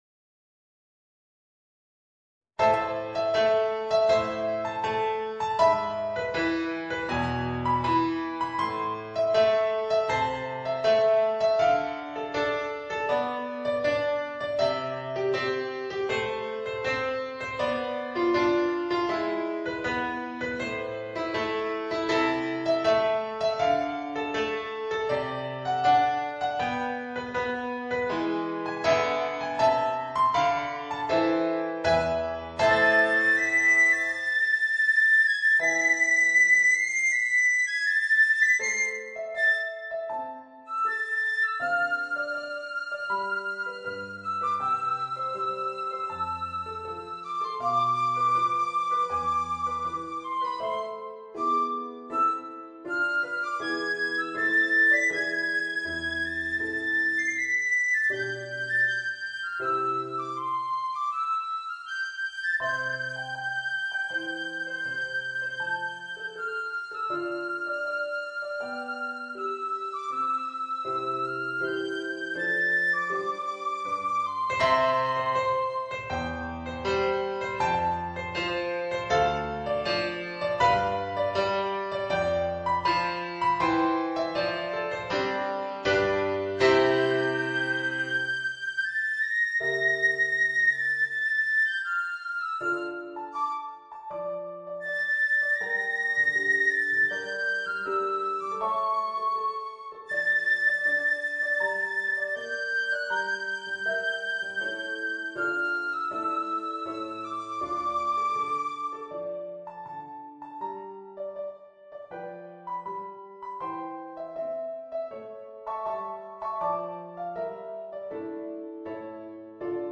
Voicing: Piccolo and Piano